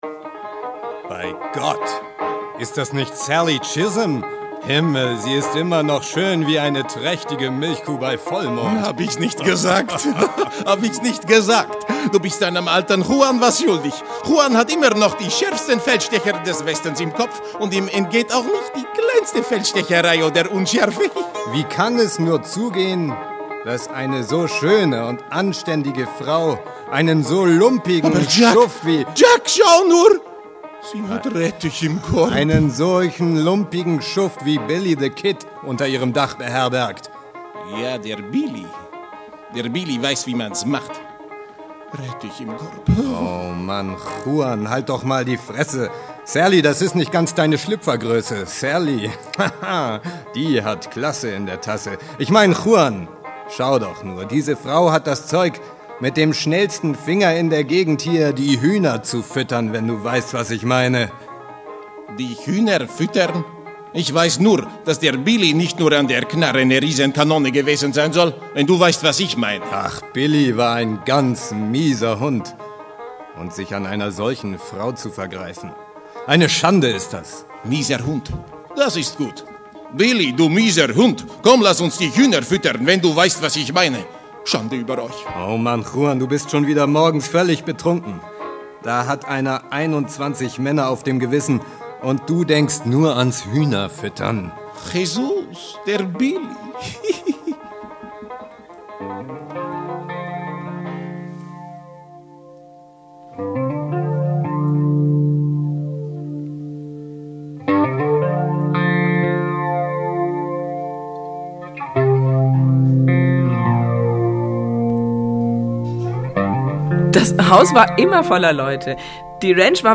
Live-Hörspiel von sprechundschwefel
Vorsicht: es wird scharf geschossen!
Sie hören Beispiele aus unserem Auftritt in der Manufaktur Schorndorf: